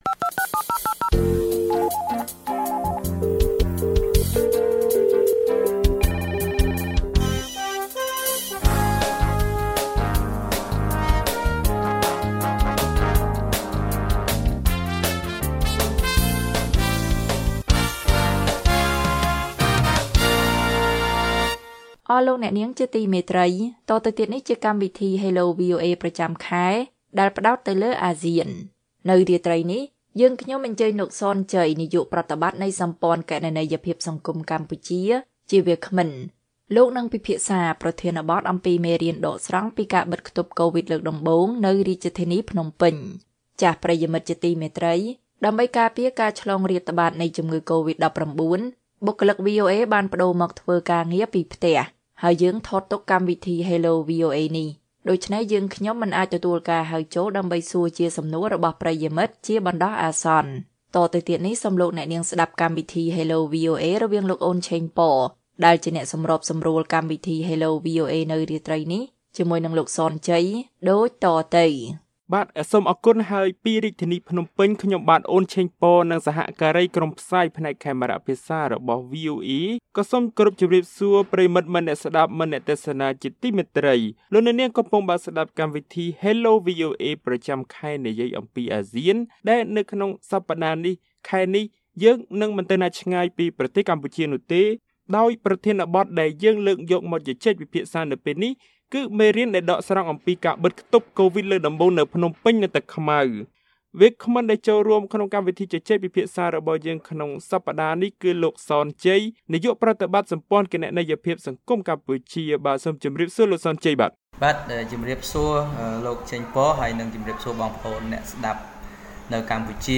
បានចូលរួមជាវាគ្មិនជជែកពីបញ្ហានេះ